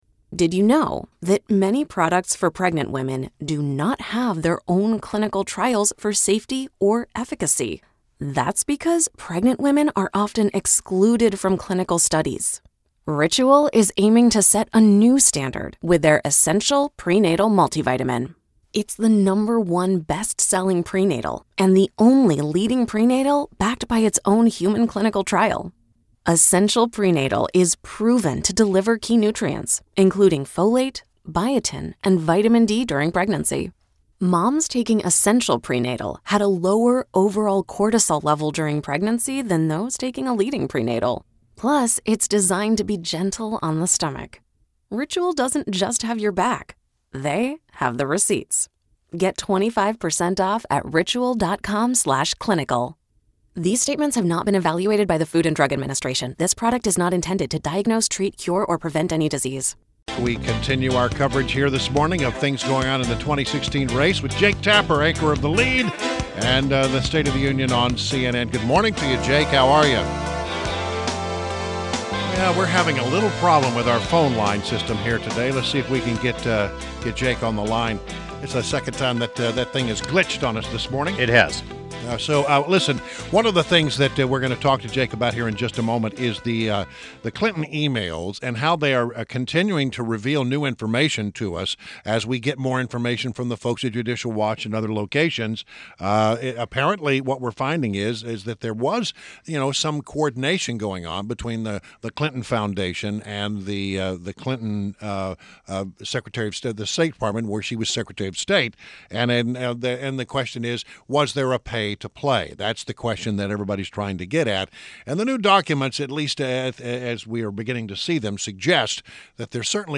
WMAL Interview - JAKE TAPPER - 08.11.16